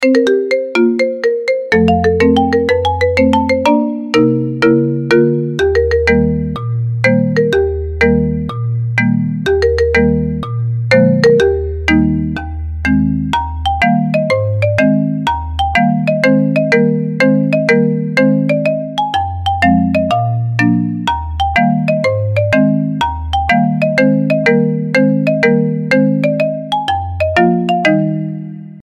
หมวดหมู่: เสียงเรียกเข้า
ซึ่งเล่นพร้อมเสียง Marimba ที่ได้รับความนิยมบน TikTok